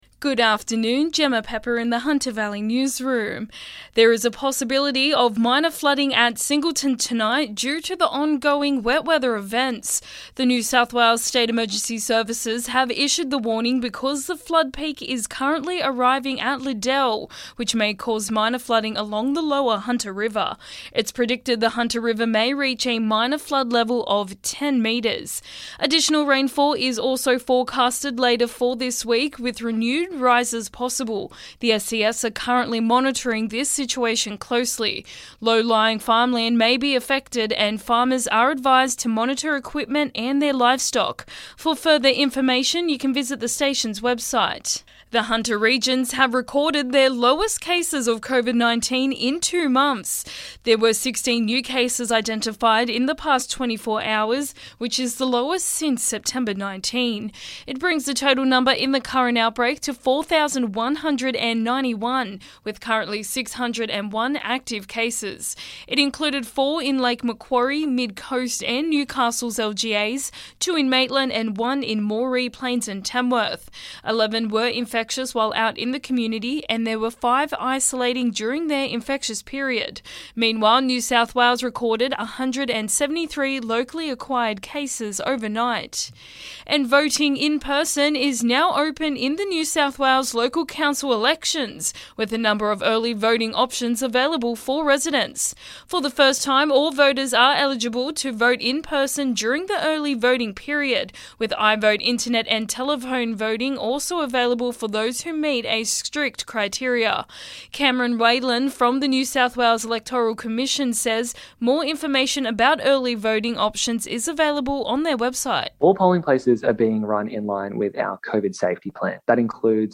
LISTEN: Local Hunter Valley News Headlines 23/11/21